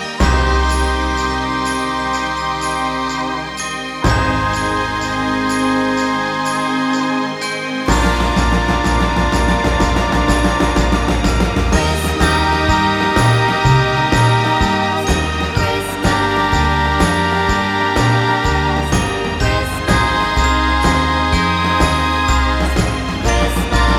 no Backing Vocals Christmas 2:47 Buy £1.50